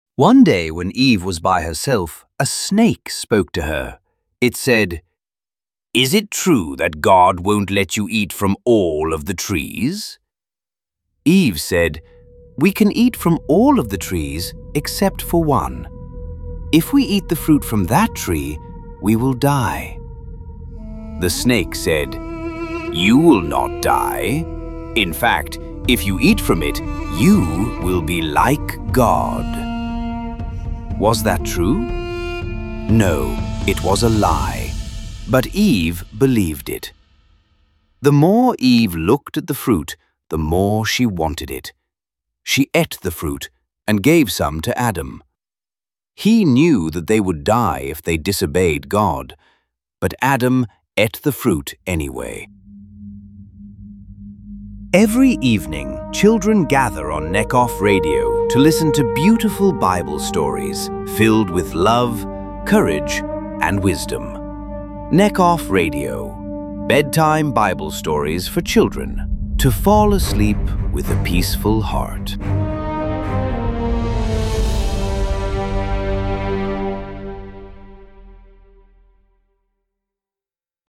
What to expect : Inspiring Bible stories Positive values for children A calm and soothing moment to end the day Subscribe to Necof Radio to hear a new bedtime story every evening, bringing light and hope to young hearts.